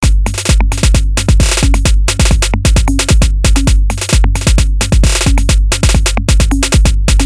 In following example, various multi triggers are used for the snare drum. Here a screenshot of the 32 step sequence (which is played 2 times):